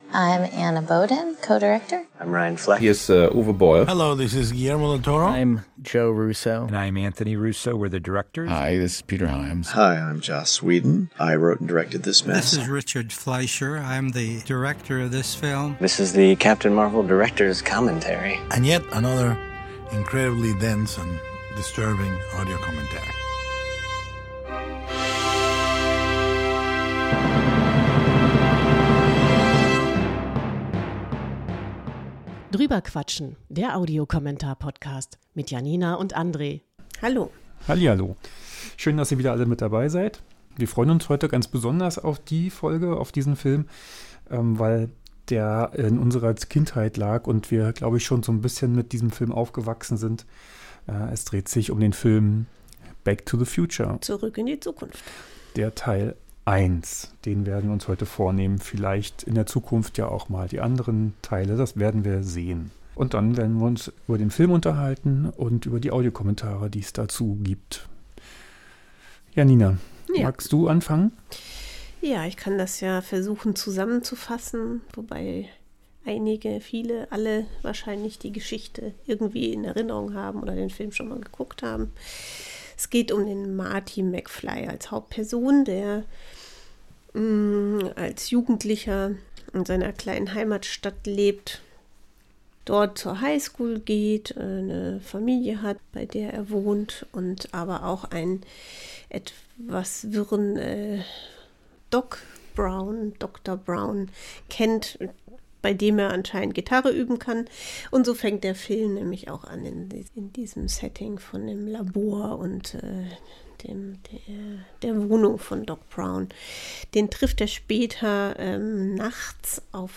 Back to the Future von 1985, mit zwei Audiokommentaren
Ein Audiokommentar